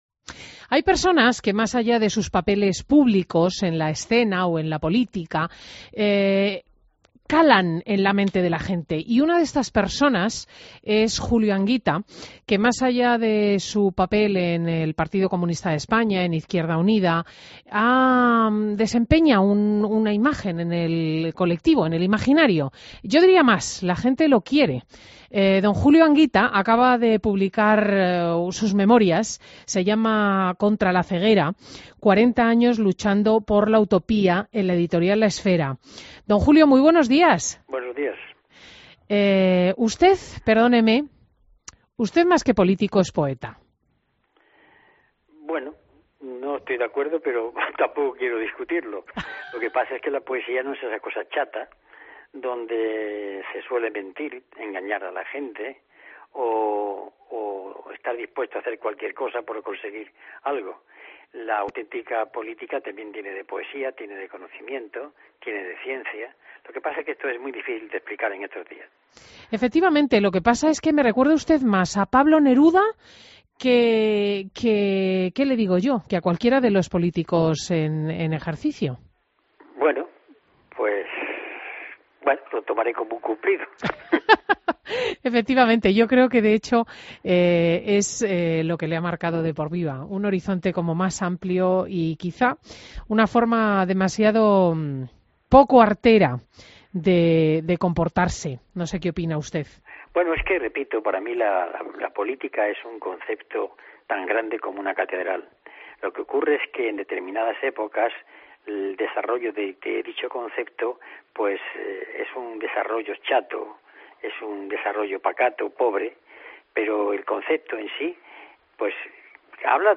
AUDIO: Entrevista a Julio Anguita en Fin de Semana COPE